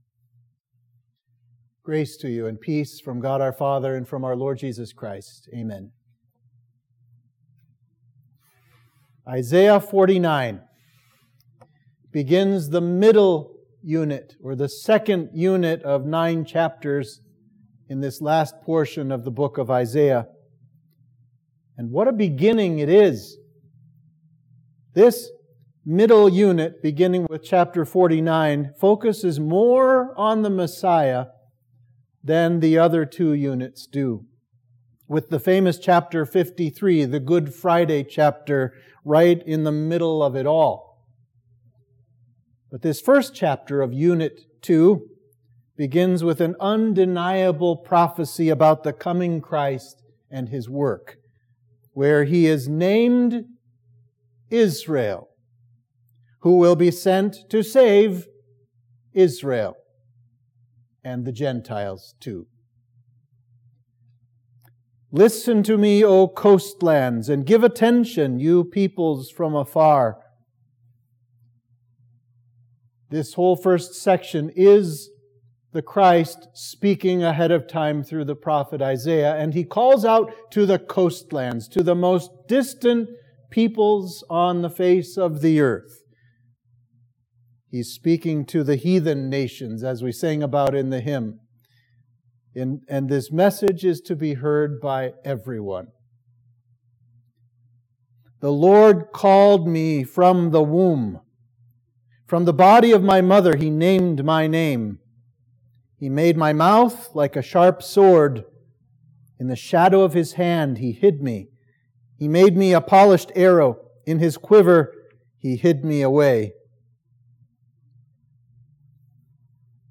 Sermon for Midweek of Trinity 2